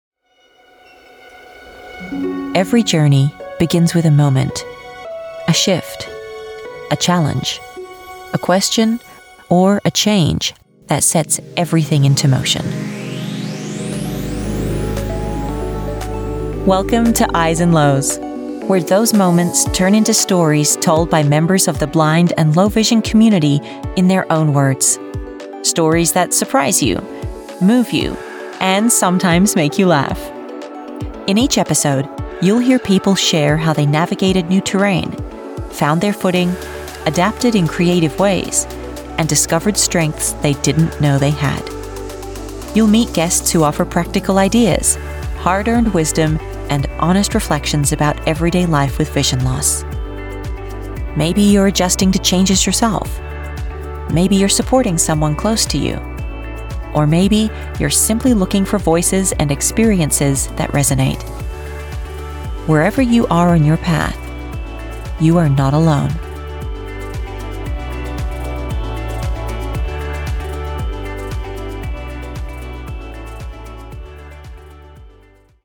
Podcast Voice Overs
Adult (30-50) | Yng Adult (18-29)